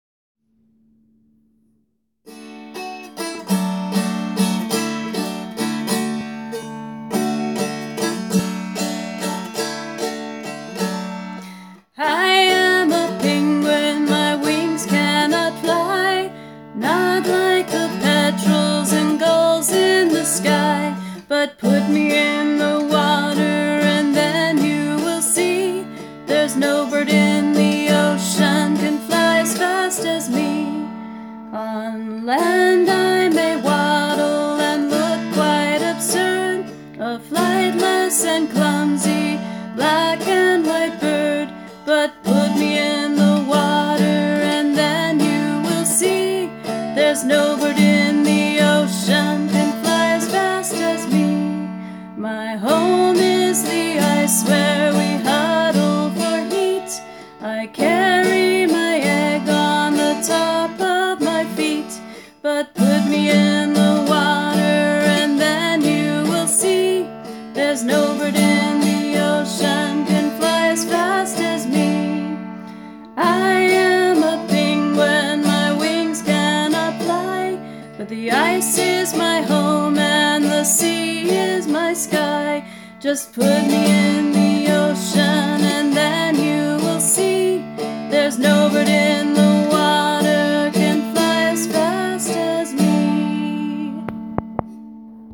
I didn’t know of any good kids songs about penguins, so I wrote this one.  I played it on the dulcimer, a Christmas present from my in-laws that I am enjoying.